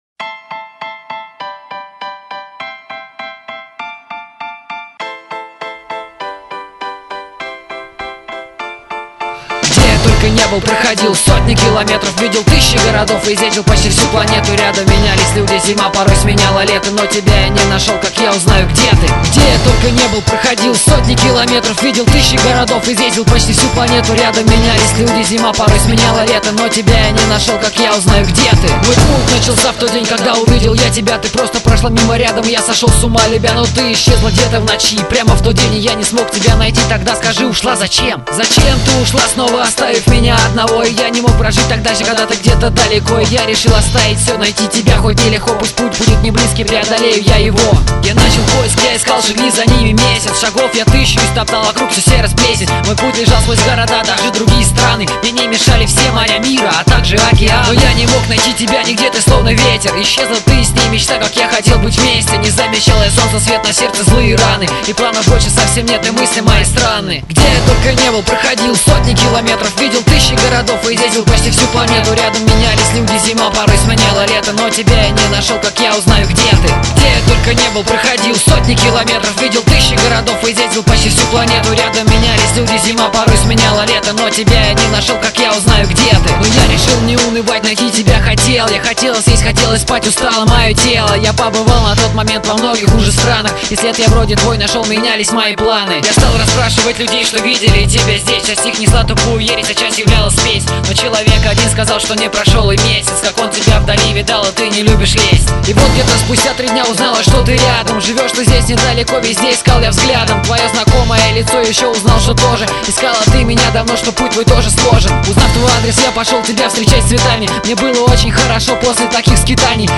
• Жанр: Рэп
kosyaki so svedeniem =))